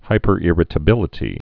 (hīpər-ĭrĭ-tə-bĭlĭ-tē)